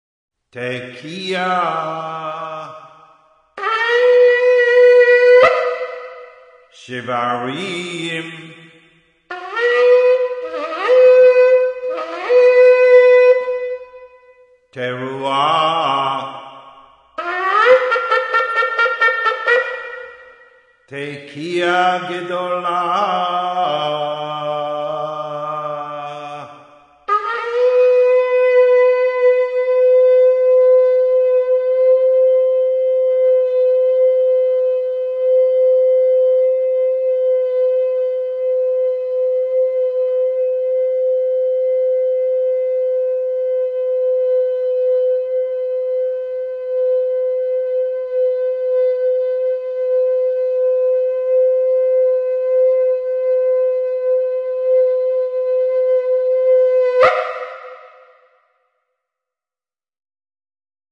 Tekiah, Shevarim, Tekiah
Ram's horn
13shofar_sounding.mp3